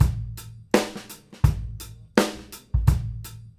GROOVE 110AL.wav